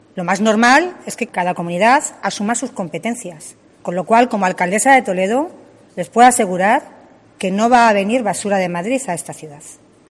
A preguntas de los medios, la primera edil toledana ha asegurado que eso "no se va a producir" en la ciudad ya que es "insostenible" desde el punto de vista medioambiental, pues el Ecoparque de Toledo no tiene espacio suficiente para acoger los residuos de otra comunidad.